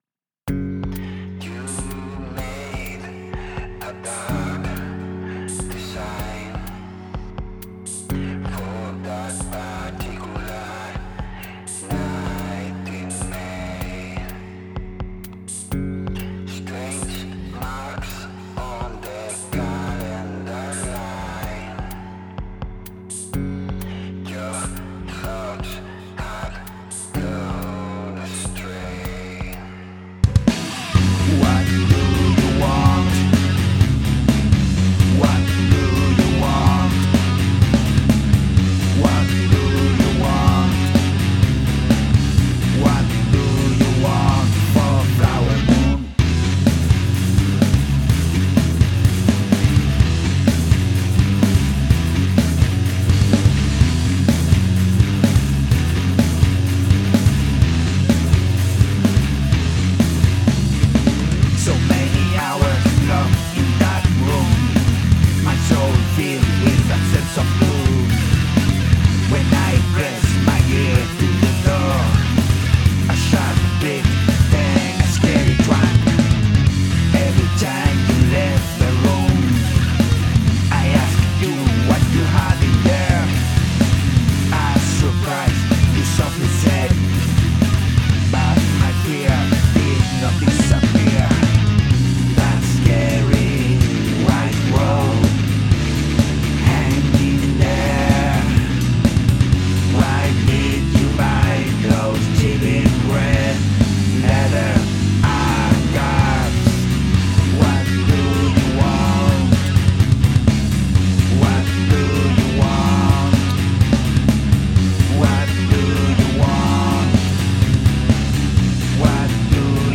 Narrative song about a murder